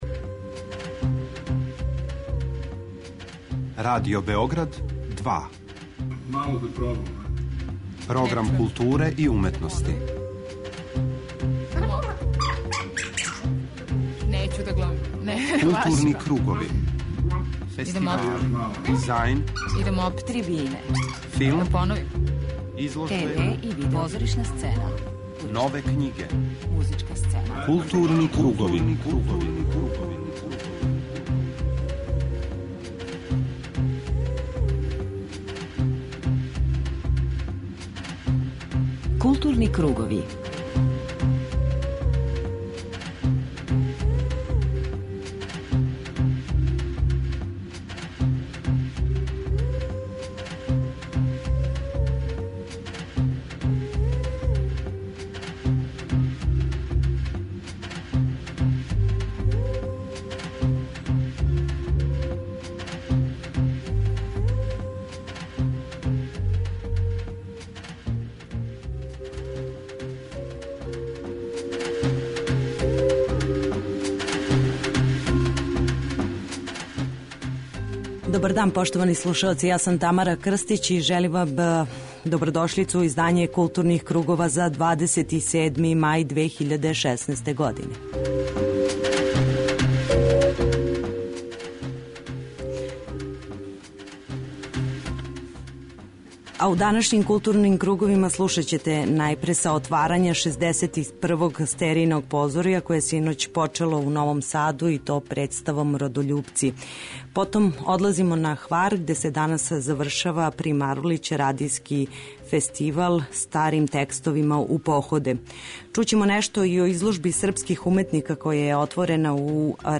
У Гутенберговом одговору, слушаћете стихове и разговоре са учесницима Фестивала.